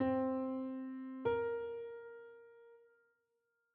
Minor 7th
C-Minor-Seventh-Interval-S1.wav